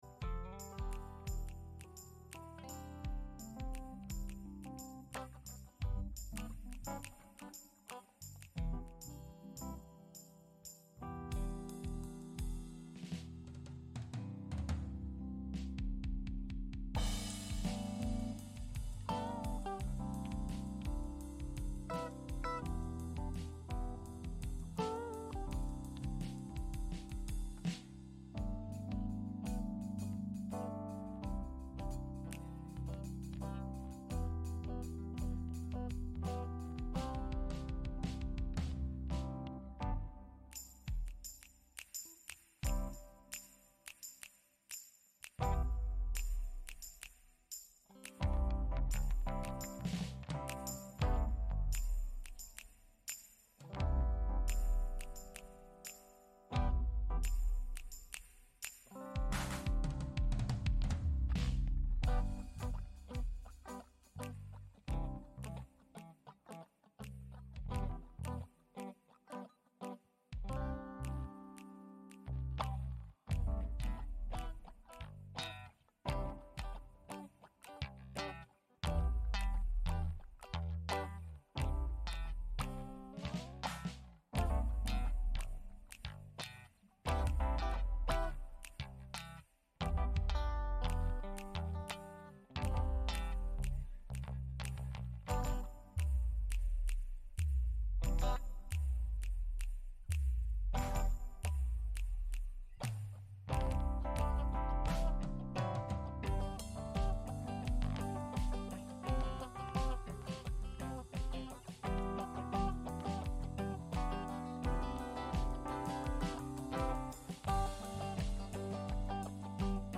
Radiogottesdienst am 3. März aus der Christuskirche Altona